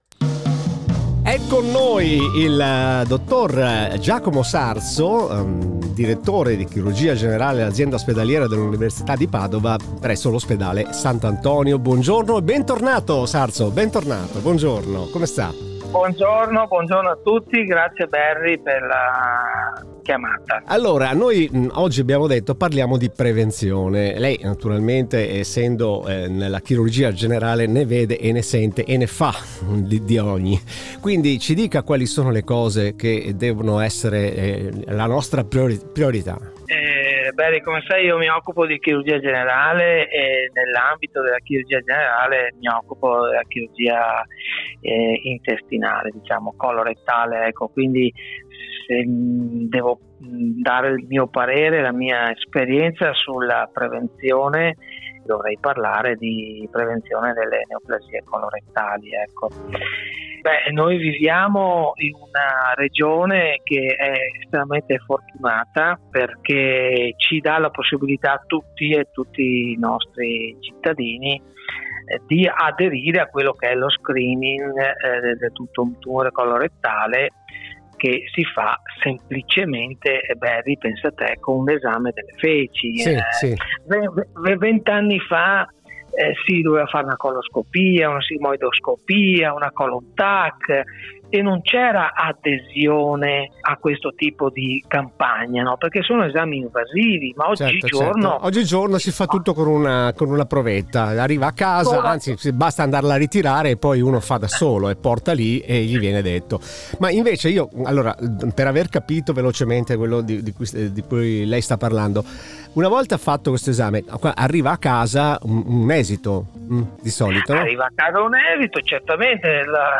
Intervista Radio Caffè